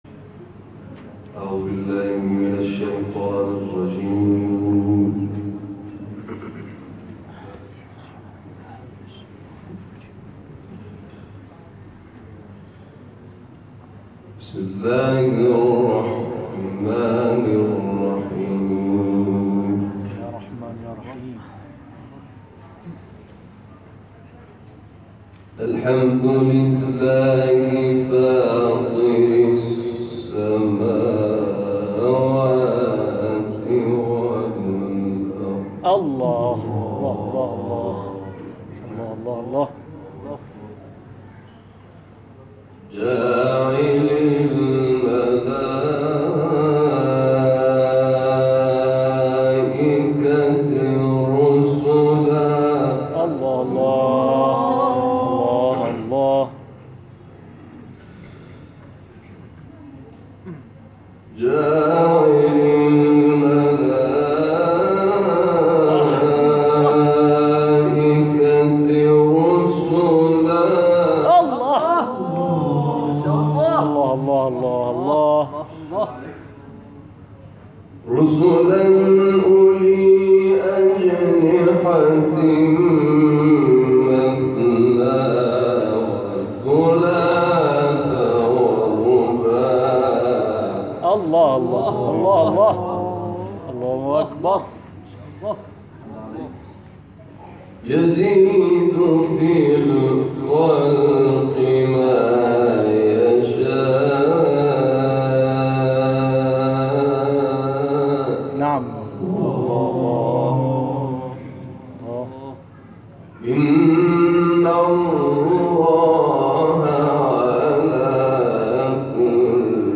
تلاوت
در زاهدان